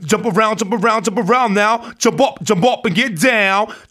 RAPHRASE10.wav